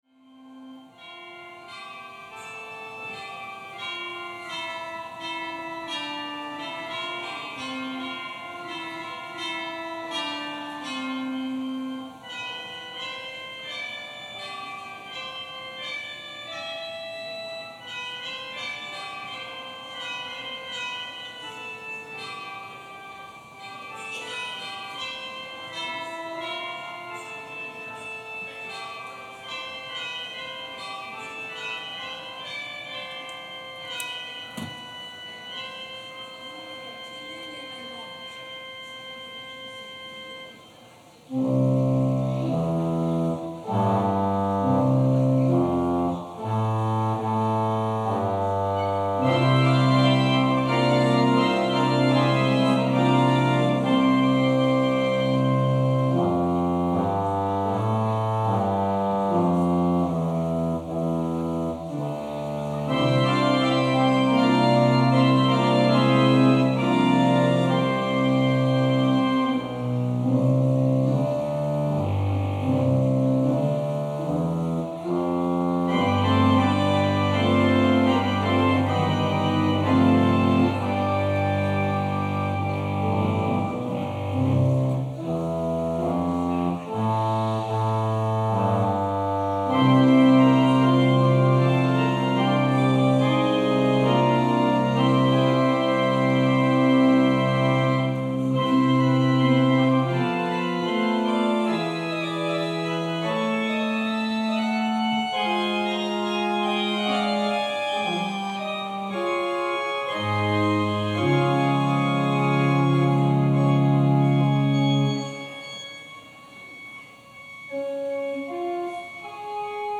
Passage: John 20: 1-18 Service Type: Holy Day Service Scriptures and sermon from St. John’s Presbyterian Church on Sunday